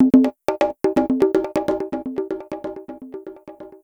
PERCUSSN027_DISCO_125_X_SC3(R).wav